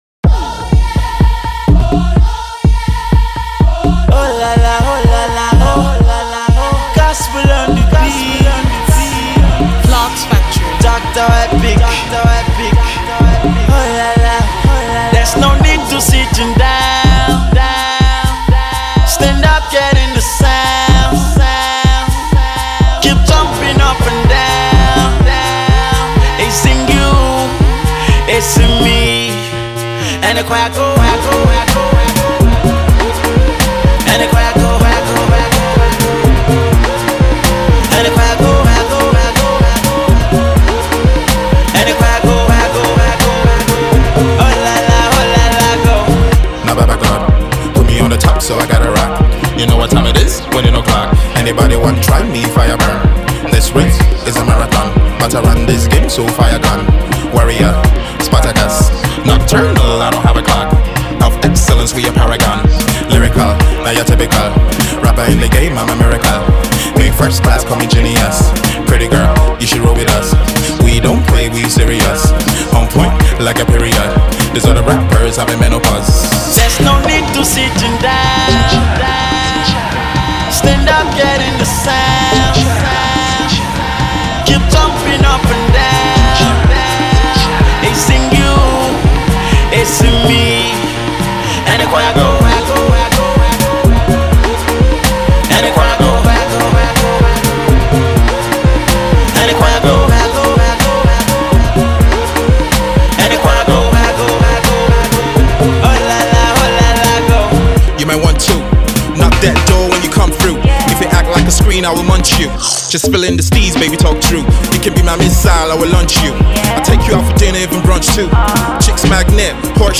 somberly enters the track with catchy tunes and witty rhymes